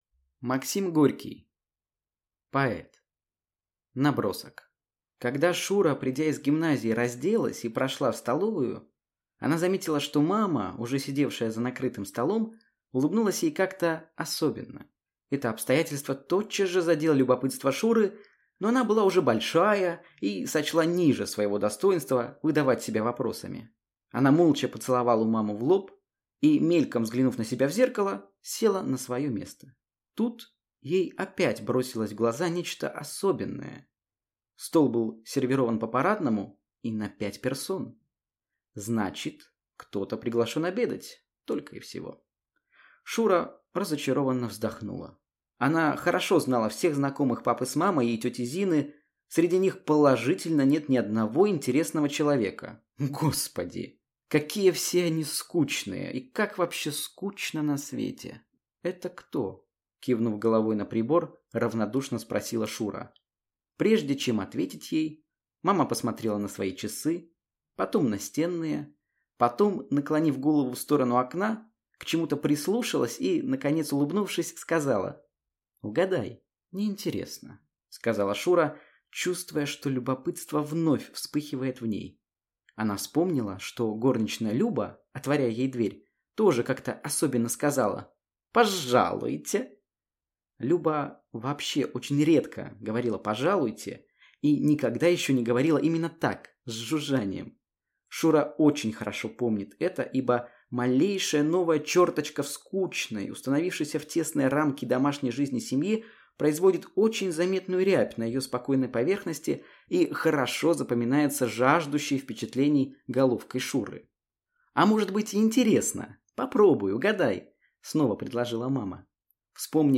Аудиокнига Поэт | Библиотека аудиокниг